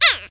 yoshi_uh2.ogg